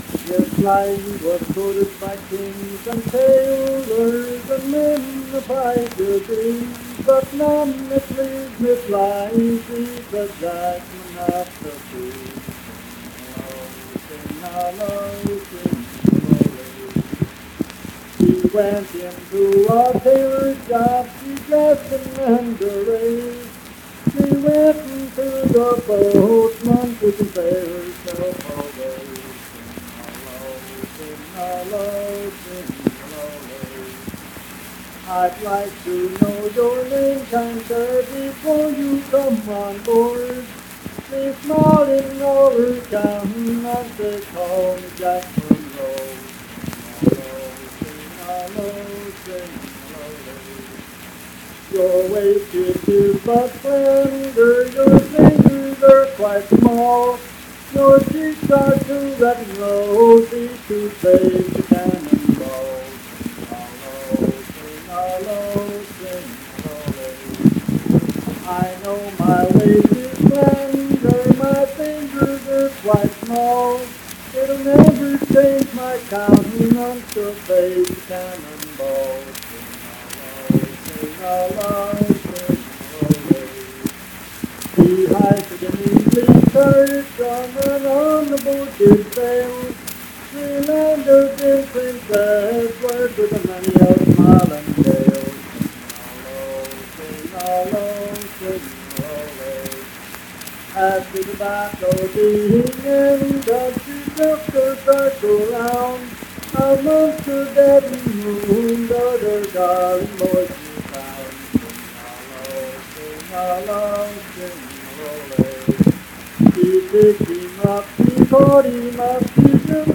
Unaccompanied vocal music performance
Verse-refrain 1(5).
Voice (sung)